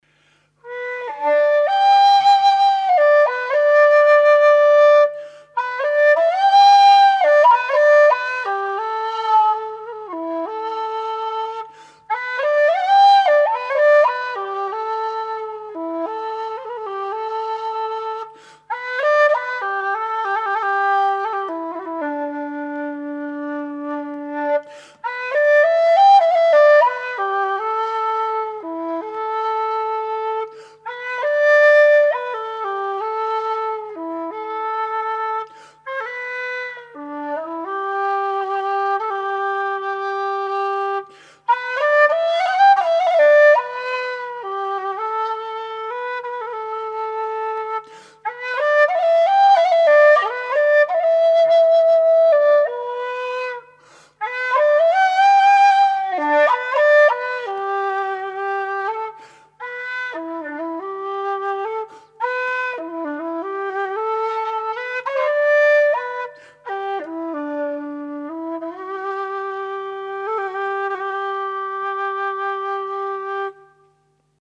Low D whistle
made out of thin-walled aluminium tubing with 23mm bore
Audio:LowD-impro2.mp3:) (Improvisation 2 [with extra reverb])